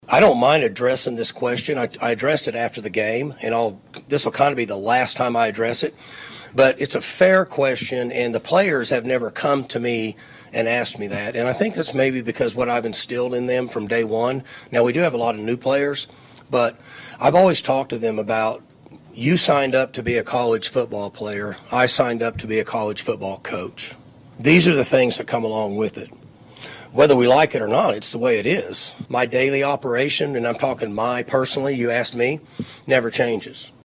Oklahoma State head football coach Mike Gundy had an interesting press conference on Monday in Stillwater, as he continues to face major questions about his future.
Gundy Presser Cut.mp3